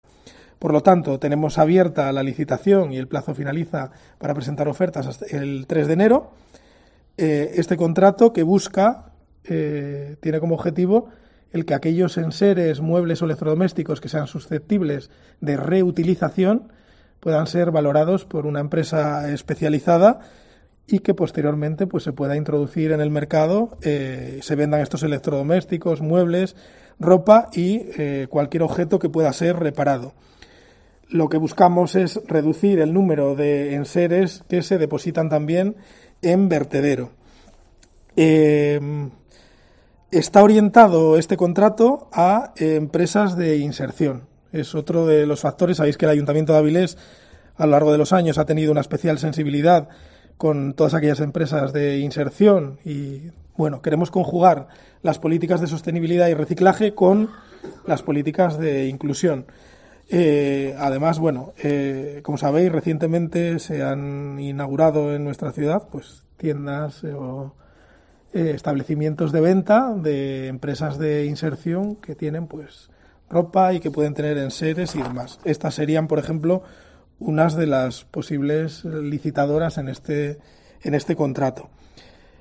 Declaraciones de Pelayo García, concejal de Medio Ambiente